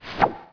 SWOOSH.WAV